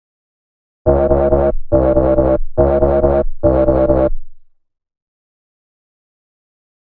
描述：140 bpm的dubstep低音。由自制的贝斯样本制成（标语有更多信息）。
Tag: 低音 回响贝斯 循环 摆动